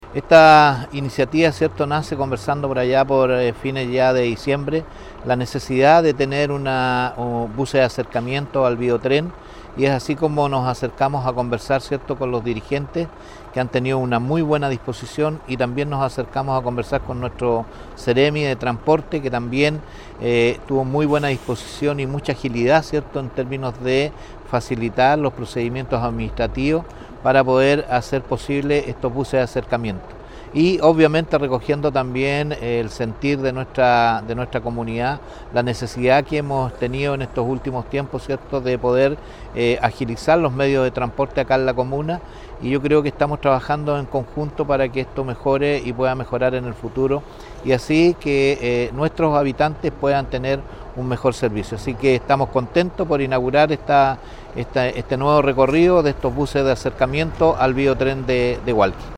El alcalde Fuentes, por su parte, afirmó que acercar el transporte público a los distintos puntos de la comuna “es una necesidad (…) estamos trabajando en conjunto para que esto mejore y pueda seguir así en el futuro”.